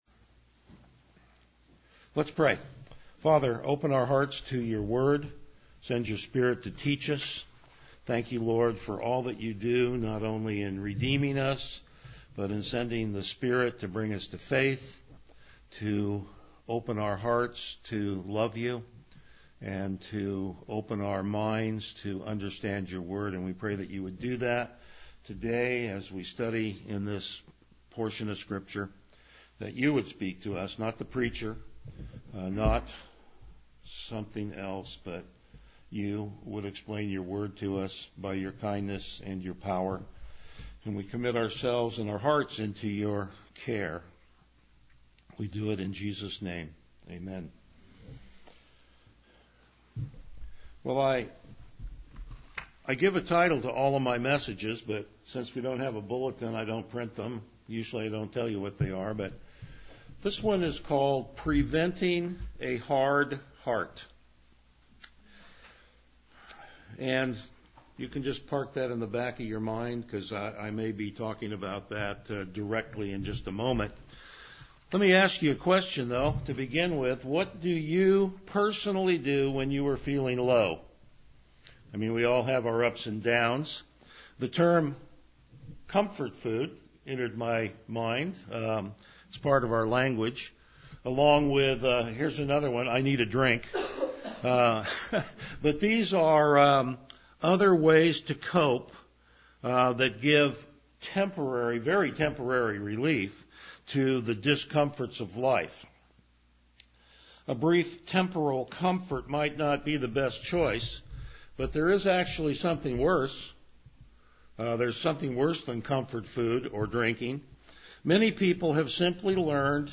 Passage: Hebrews 3:1-14 Service Type: Morning Worship